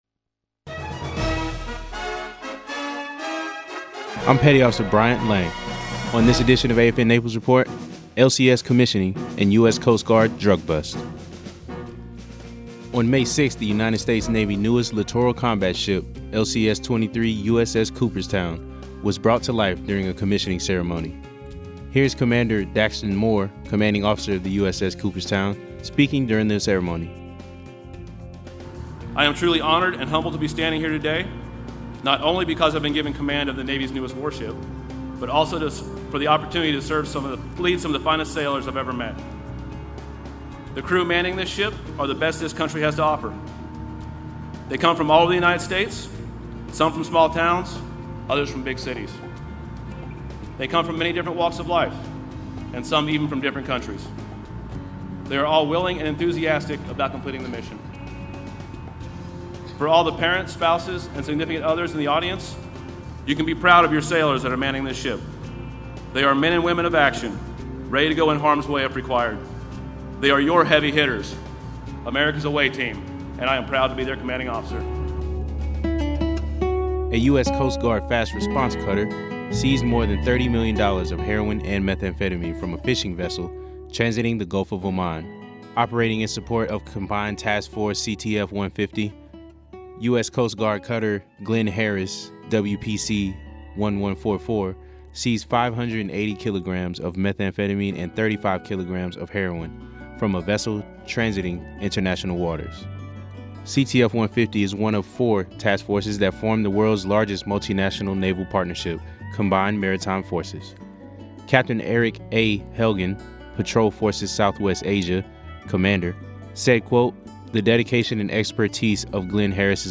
AFN Naples Regional News - LCS Commissioning and Coast Guard Drug Bust